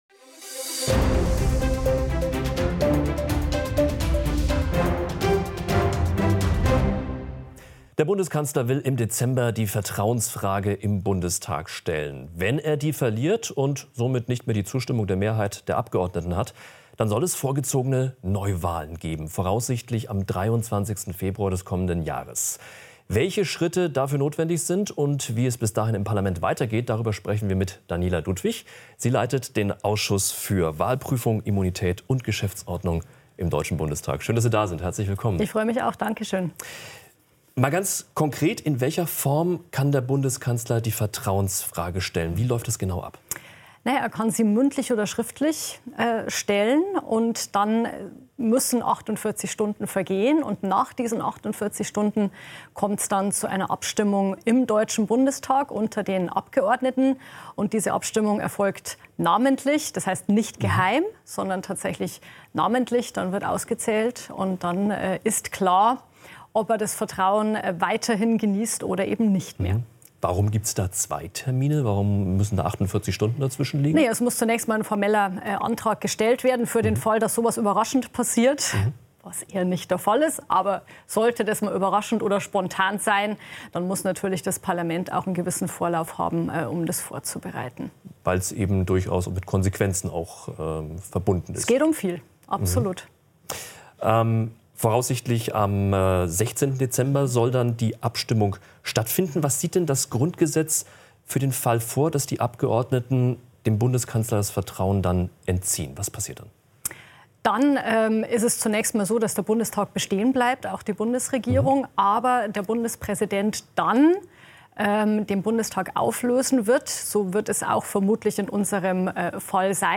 Interviews - Audio Podcasts